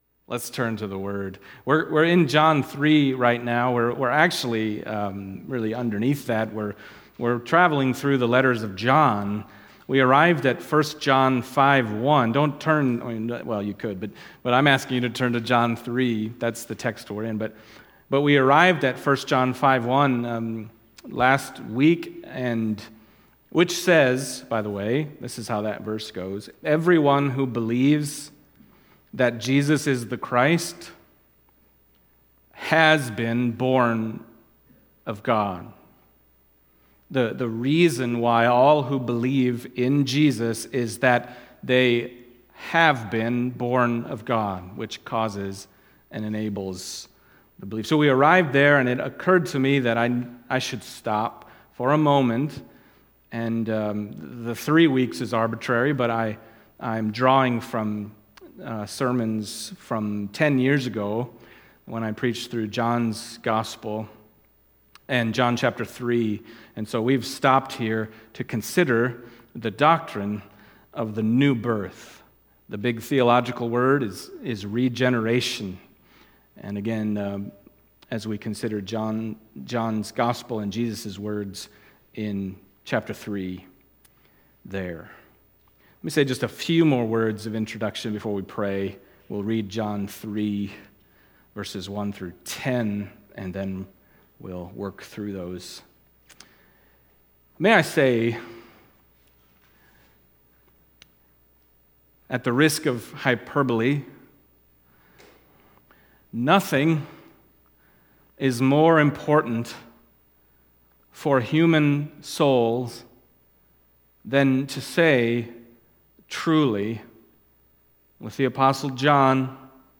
Other Passage: John 3:1-10 Service Type: Sunday Morning John 3:1-10 « You Must Be Born Again